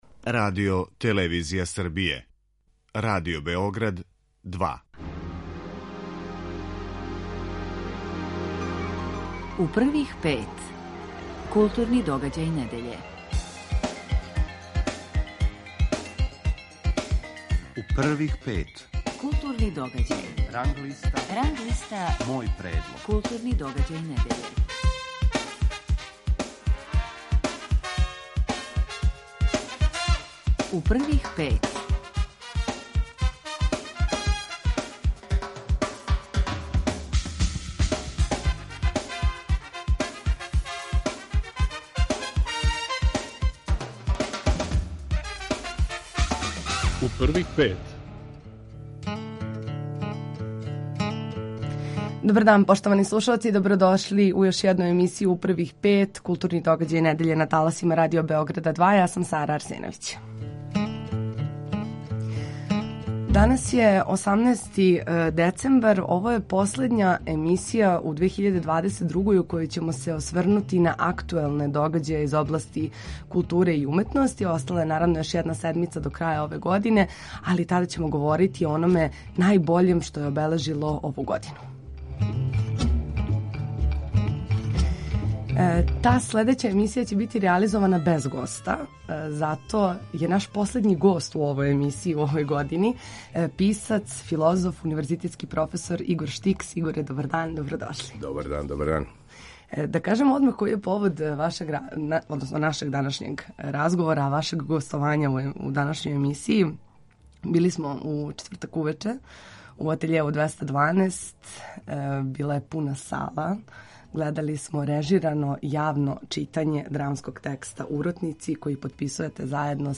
Гост емисије је писац и филозоф Игор Штикс.